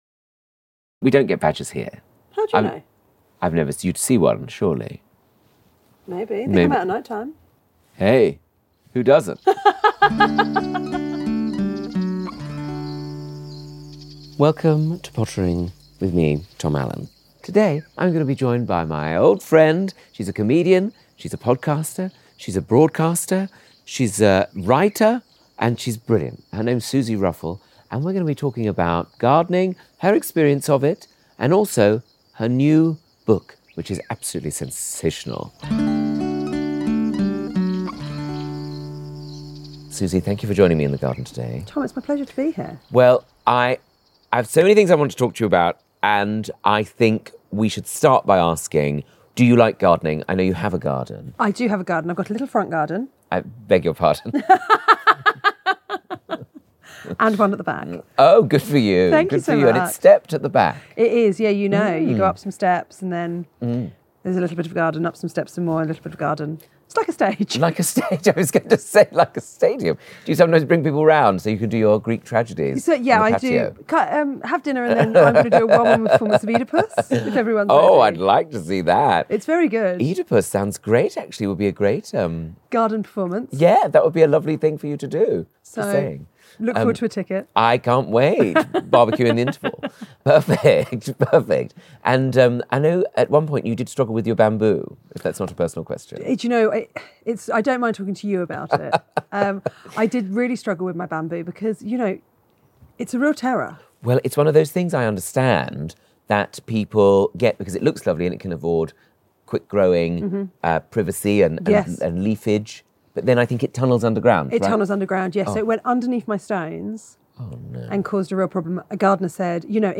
It was a delight to welcome my dear friend, the hilarious Suzi Ruffell into the garden for a chat and some light manual labour.